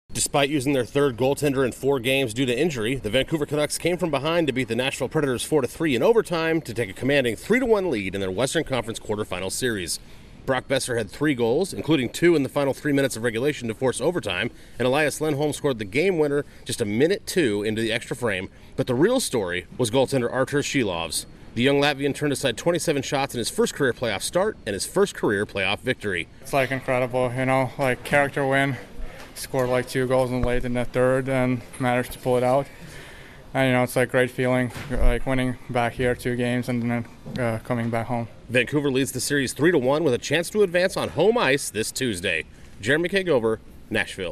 The Canucks stun the Predators to take control of their first-round series. Correspondent